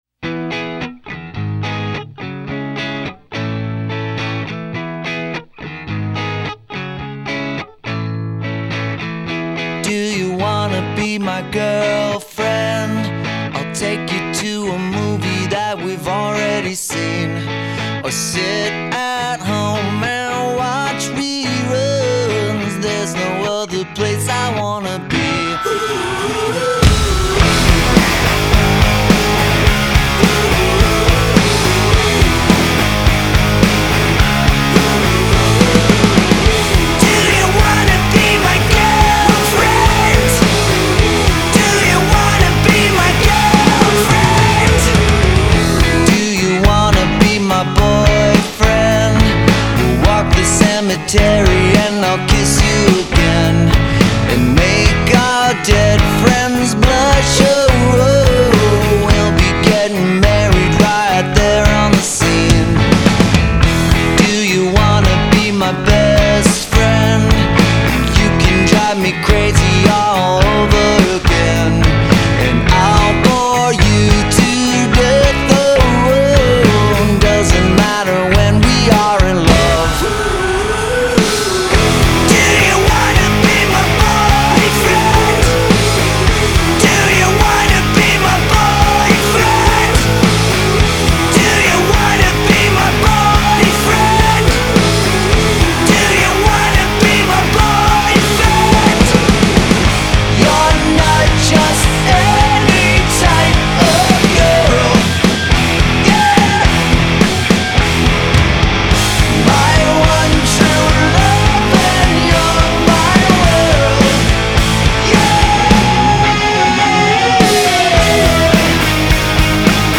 Alternative_Rock#